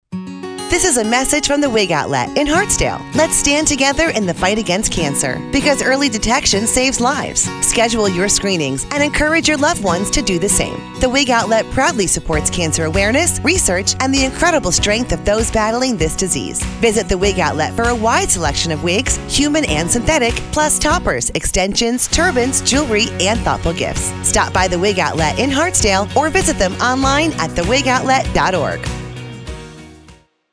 Listen to The Wig Outlet’s radio advertisement.